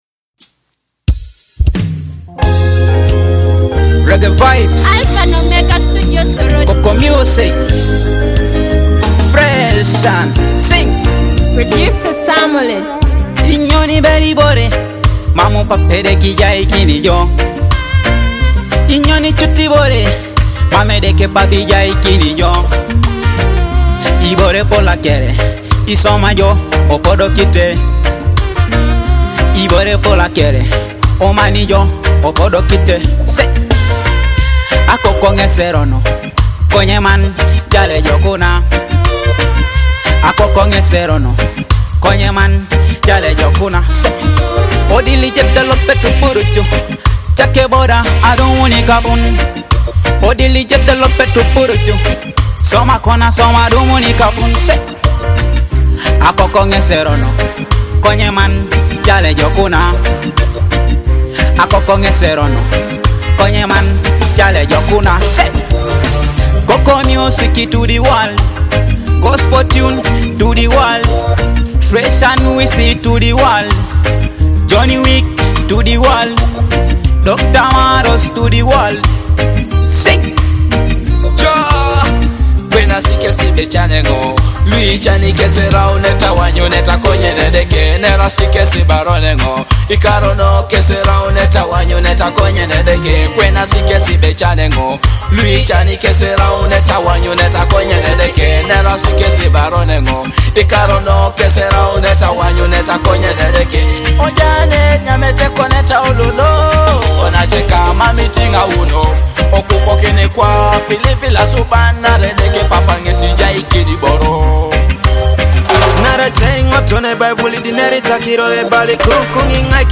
reggae anthem
Rooted in deep reggae rhythms and conscious storytelling